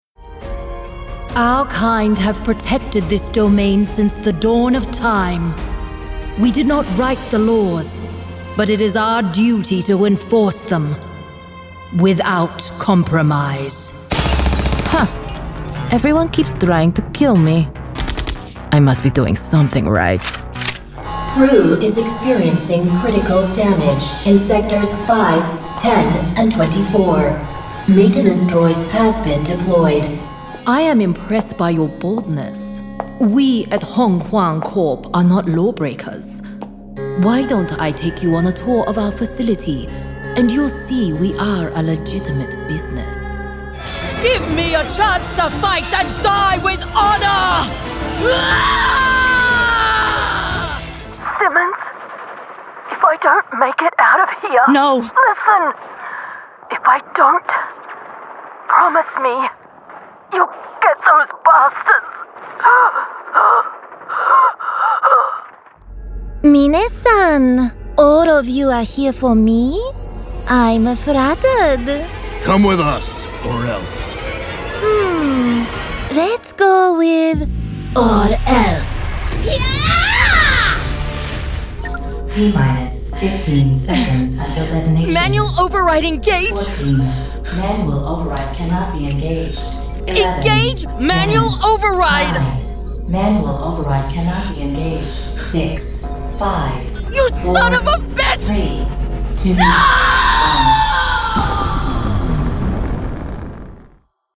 Teenager, Young Adult, Adult
australian | character
standard us | natural
GAMING 🎮